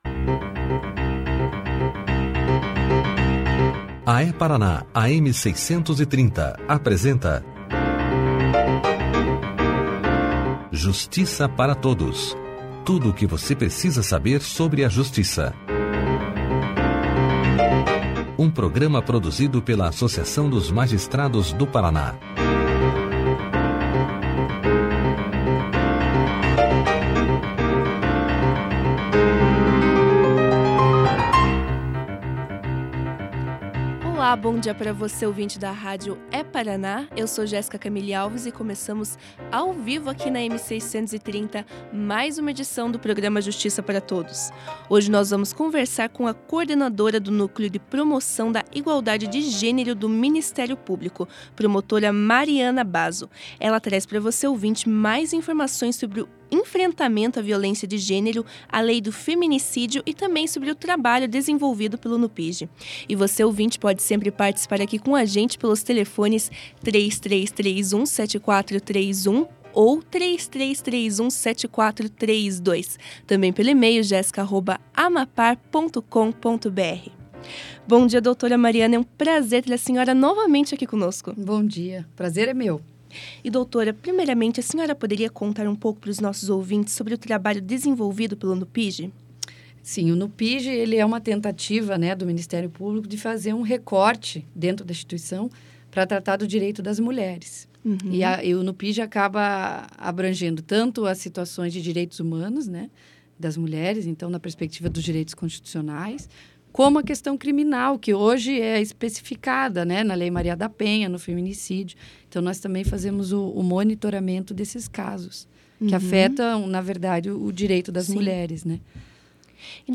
No programa dessa terça-feira (01), a promotora Mariana Seifert Bazzo contou aos ouvintes da rádio É-Paraná sobre o trabalho realizado pelo Núcleo de Promoção da Igualdade de Gênero (NUPIGE). A promotora falou sobre a relação do machismo com a violência de gênero, além de explicar sobre a importância da Lei do Feminicídio.
Clique aqui e ouça a entrevista da promotora Mariana Seifert Bazzo sobre o Núcleo de Promoção da Igualdade de Gênero na íntegra.